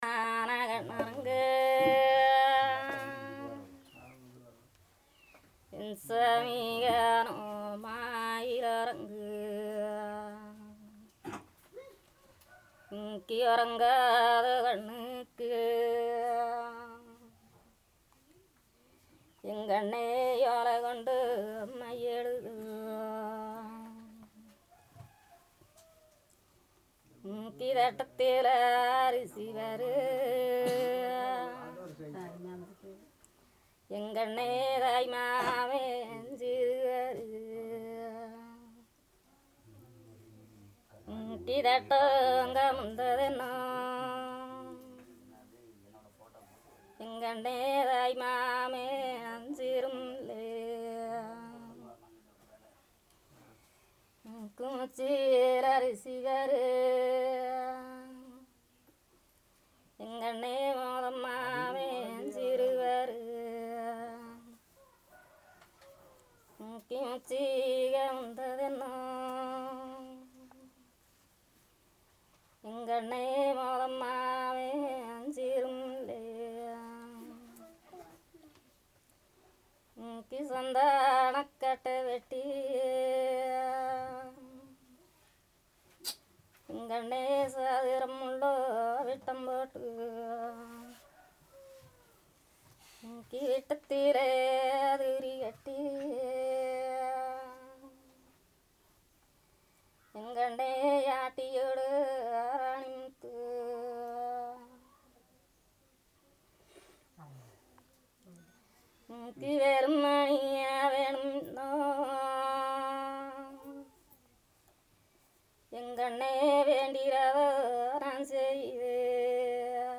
Performance of a song about family